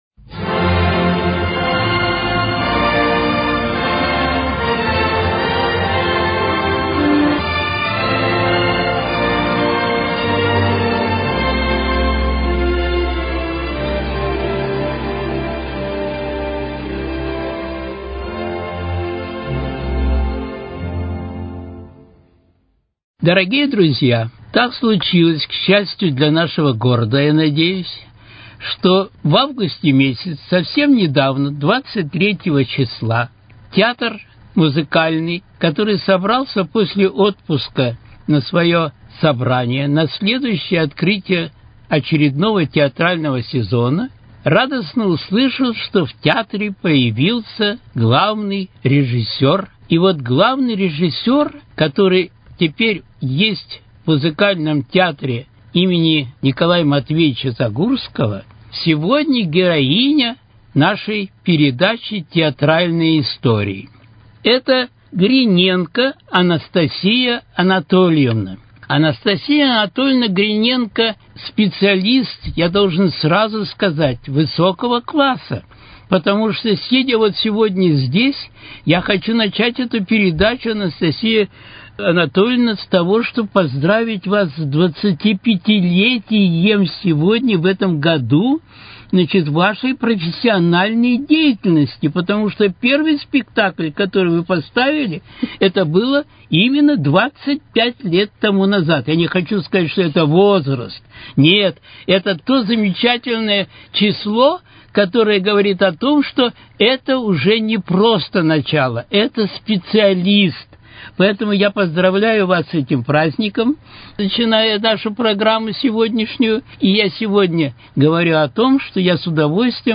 Авторский цикл передач